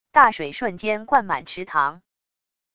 大水瞬间灌满池塘wav音频